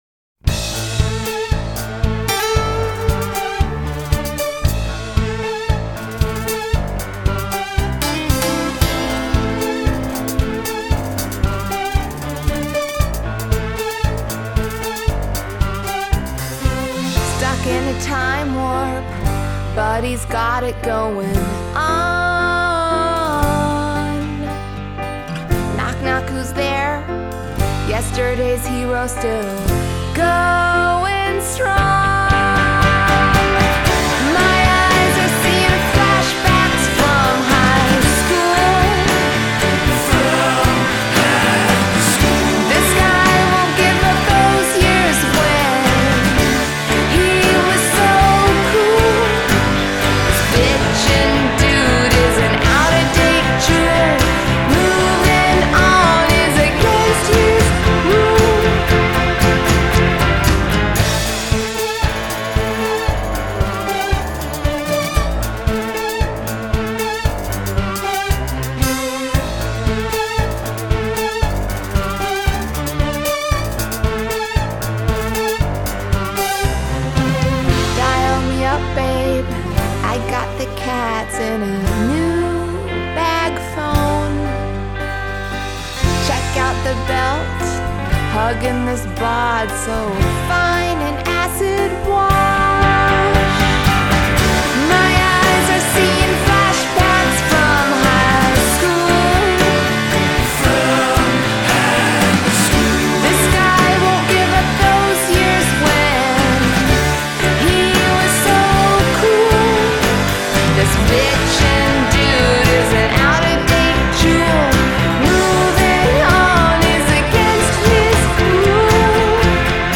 Adult Contemporary , Comedy , Indie Pop , Musical Theatre